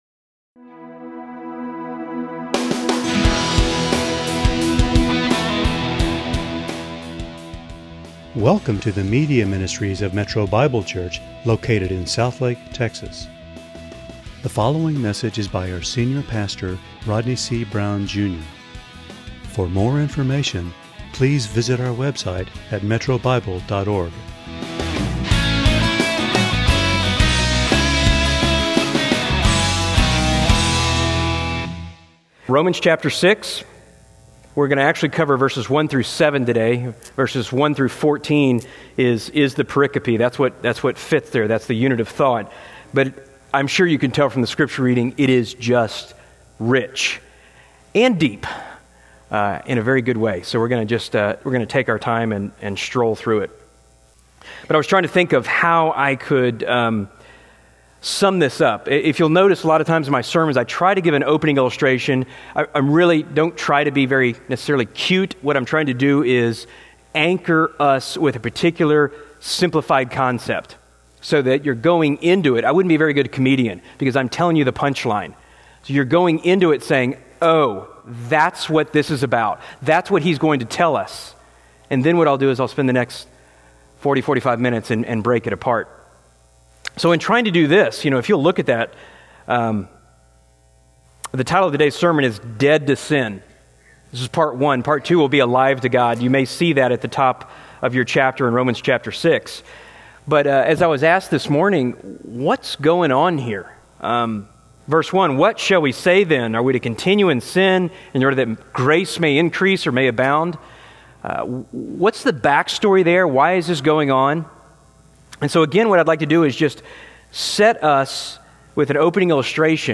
× Home About sermons Give Menu All Messages All Sermons By Book By Type By Series By Year By Book Dead to Sin Believers have died with Christ and therefore are dead to sin.